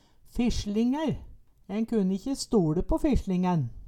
Høyr på uttala Ordklasse: Substantiv hankjønn Kategori: Karakteristikk Attende til søk